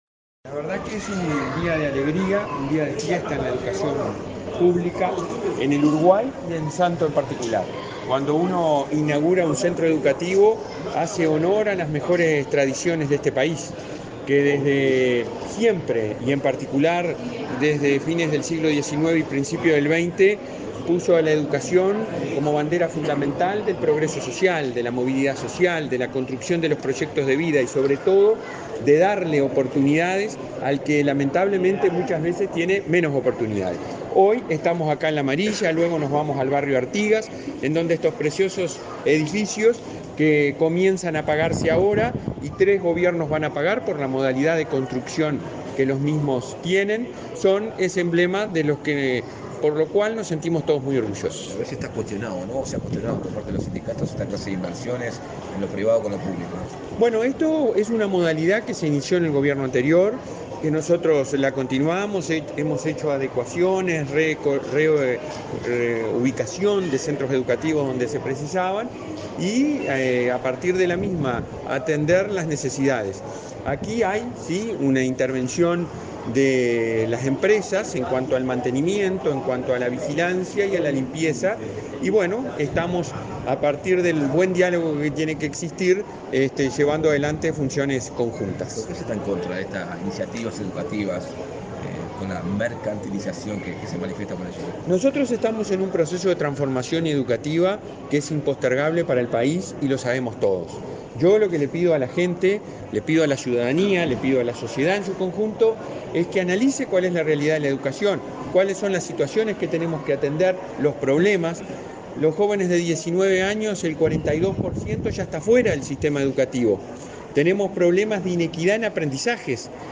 Declaraciones a la prensa del presidente del Consejo Directivo Central de la ANEP, Robert Silva
En la oportunidad, Robert Silva realizó declaraciones a la prensa.